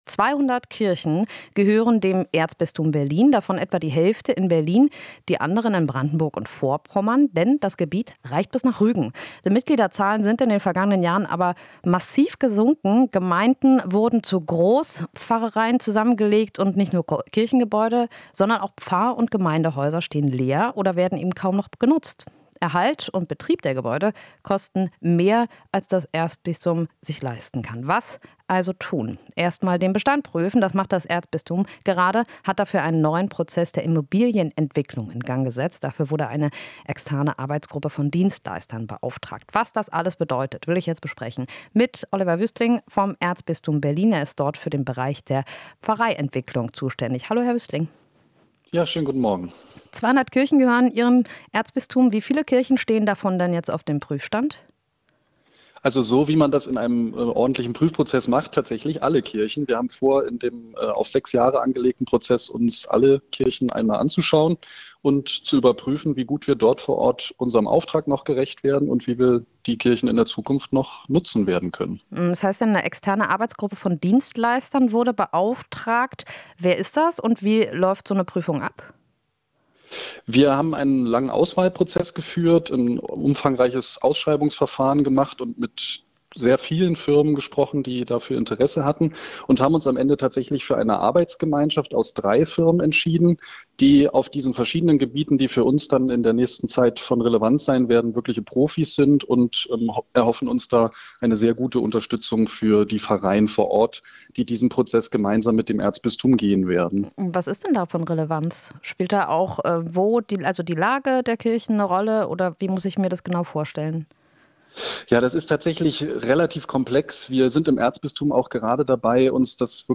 Interview - Erzbistum Berlin stellt seine Immobilien auf den Prüfstand